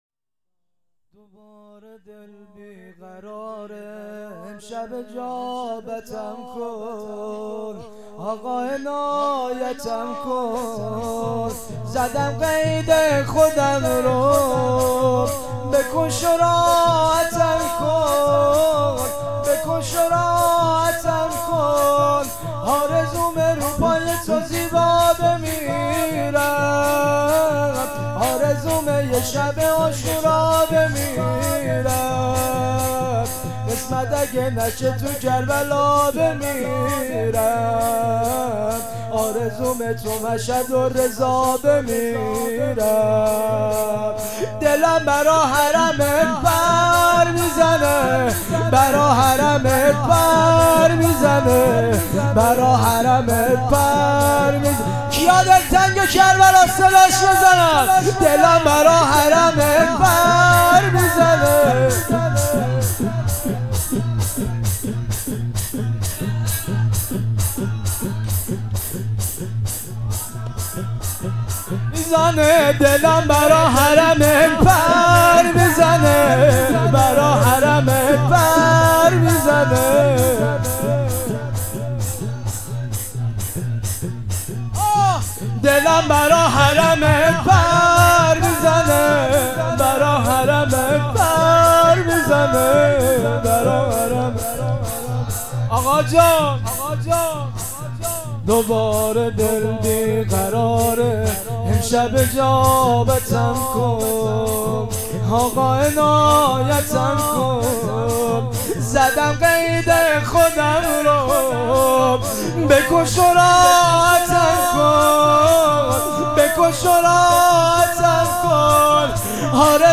مراسم هفتگی۹۸.۴.۶،شهادت امام صادق علیه السلام